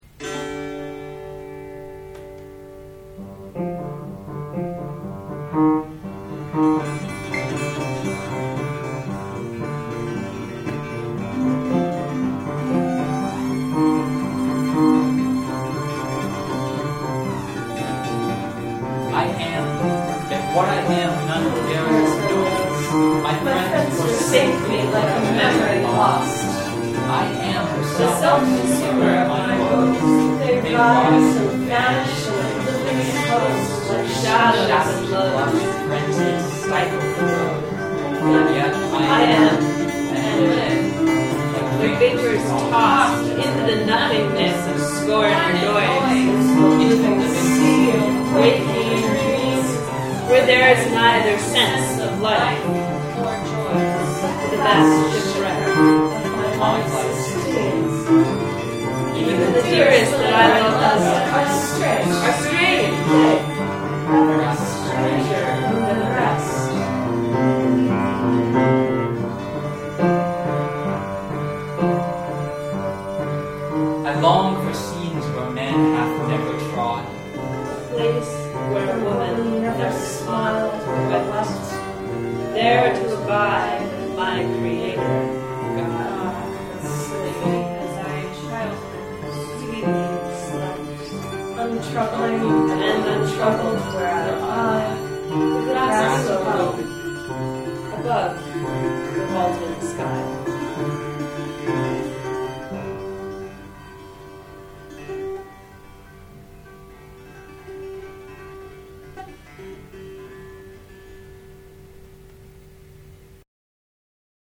These files haven’t been mastered yet, so there’s some peaking and a few volume problems, but if you want to get a quick-and-dirty MP3 sense of what we recorded yesterday, have a listen.